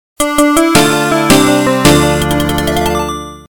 原游戏FM版，由PMDPlay导出。